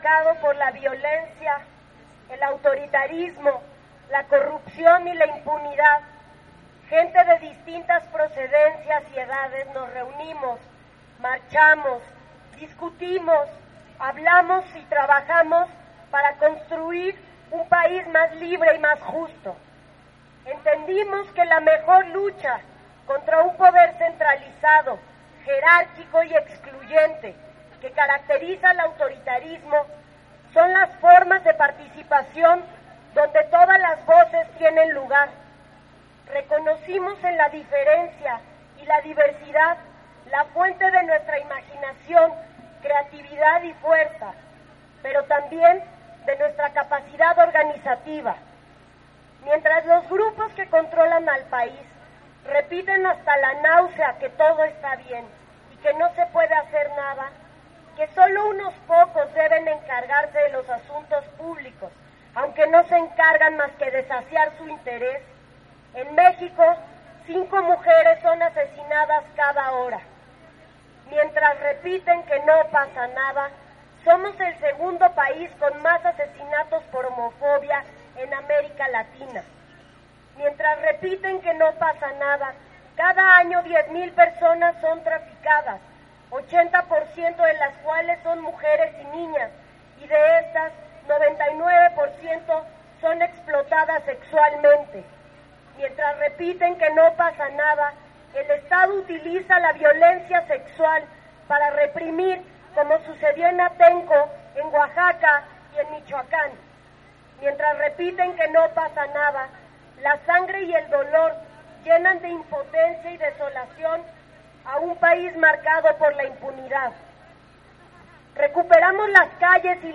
El pasado 25 de noviembre se realizó una marcha por el 25 de noviembre "día internacional contra la violencia a la Mujer"que partió del monumento a la Madre hacia el Palacio de Bellas Artes, la cual inició a medio día, esta fue convocada por colectivos feministas, como Pan y Rosas, Mujeres y la Sexta, entre otras; apróximadamente a las 2 de la tarde arribaron a la av. Juarez donde se llevó acabo la actividad político-cultural.